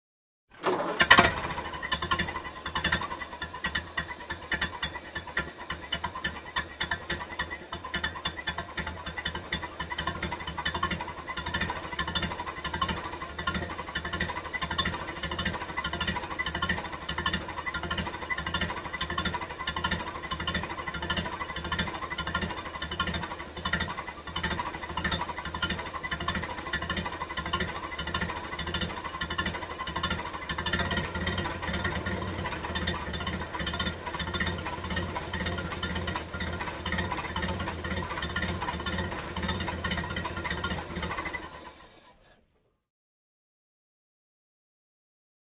Звуки поломки автомобиля
Старинный автомобиль тарахтит и глохнет